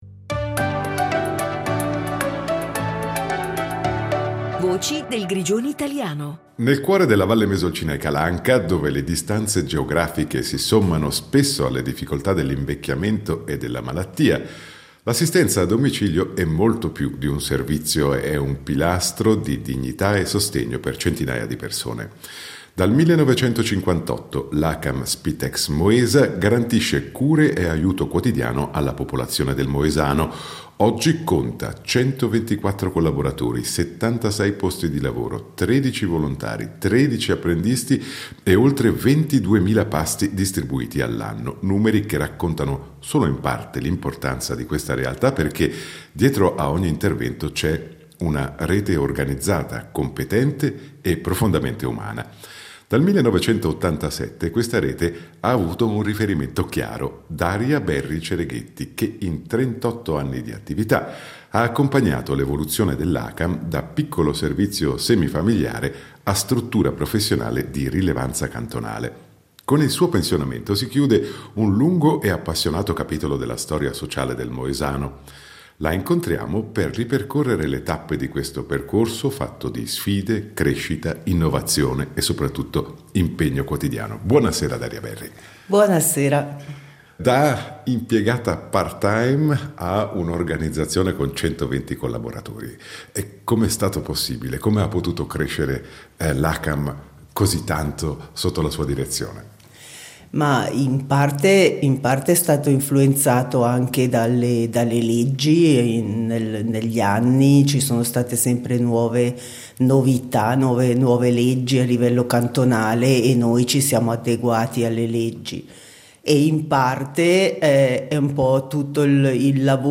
Con la sua voce pacata ma decisa